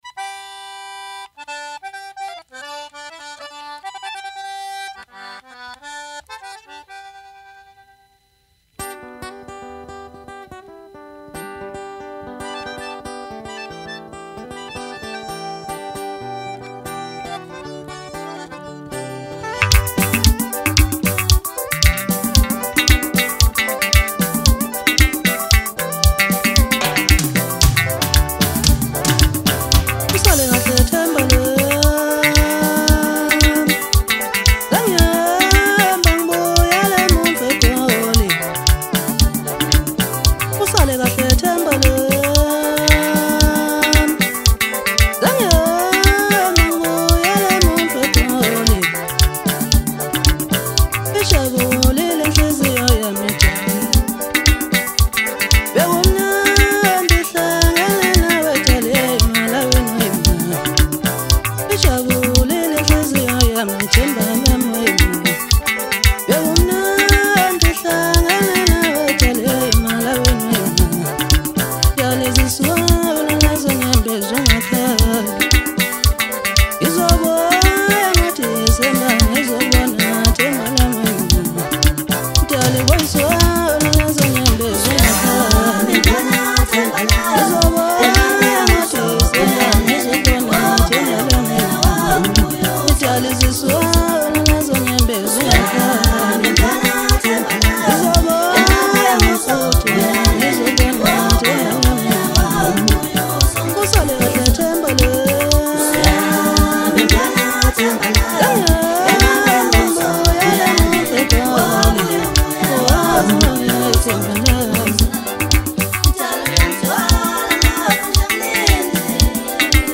Home » Maskandi Music » Maskandi